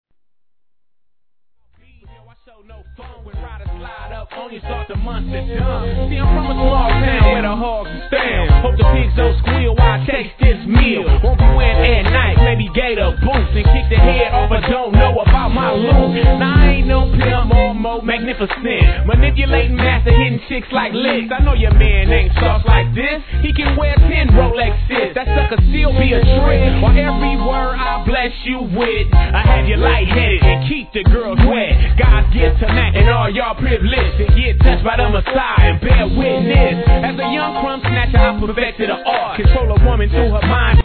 G-RAP/WEST COAST/SOUTH
ポワ〜ワ〜ンシンセWORKにやられます。